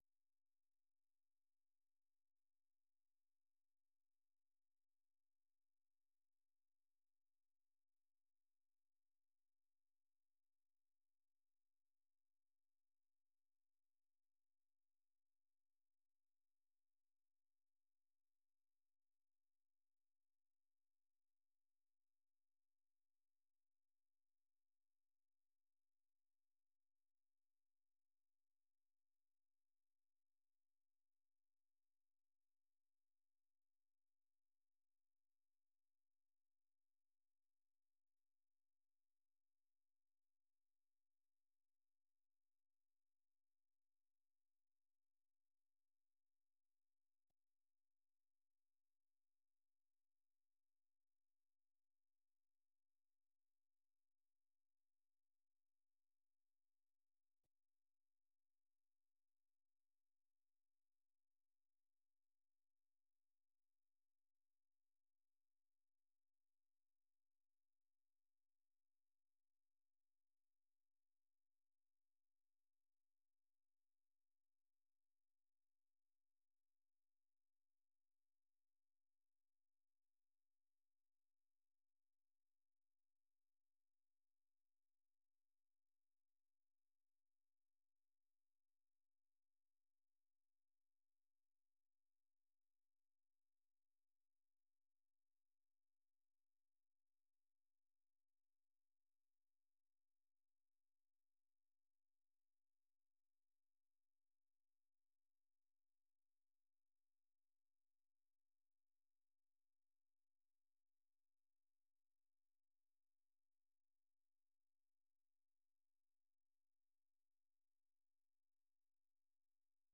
The URL has been copied to your clipboard 페이스북으로 공유하기 트위터로 공유하기 No media source currently available 0:00 0:59:57 0:00 생방송 여기는 워싱턴입니다 생방송 여기는 워싱턴입니다 아침 공유 생방송 여기는 워싱턴입니다 아침 share 세계 뉴스와 함께 미국의 모든 것을 소개하는 '생방송 여기는 워싱턴입니다', 아침 방송입니다.